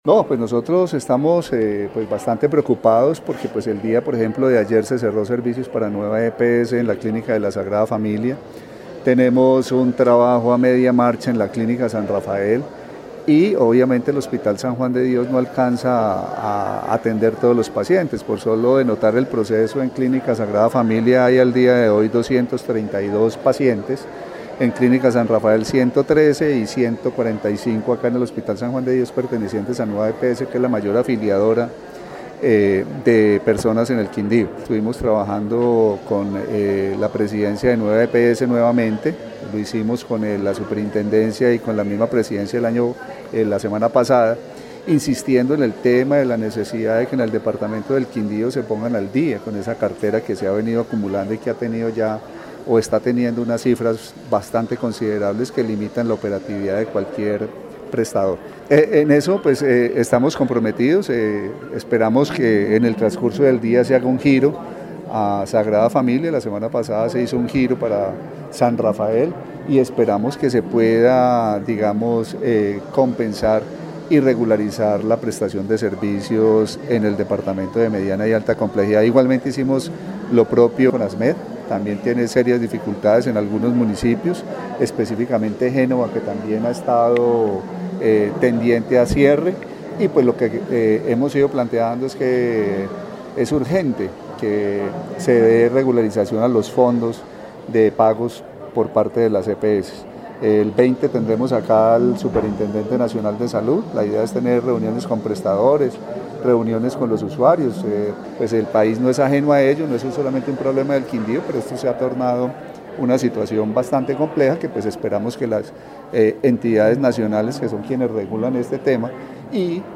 Carlos Alberto Gómez, secretario de salud del Quindío